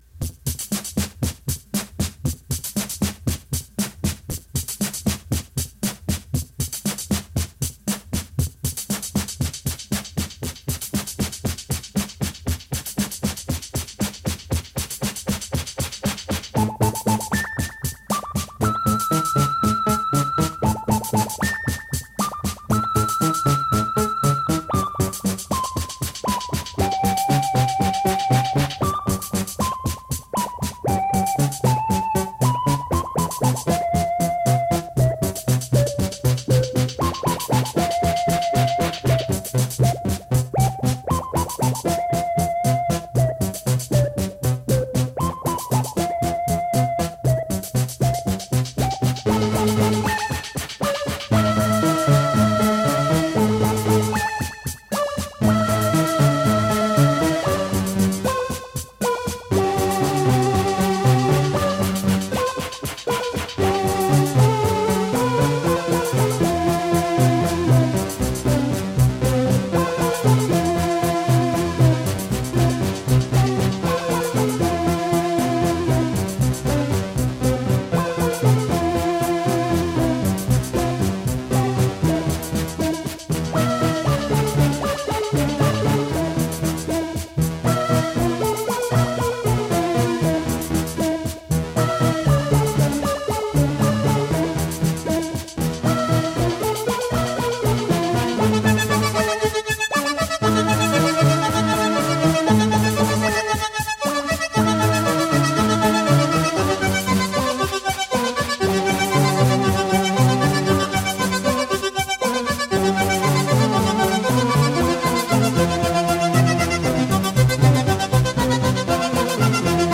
Melodic electronica.
Tagged as: Electronica, Other, IDM, Prog Rock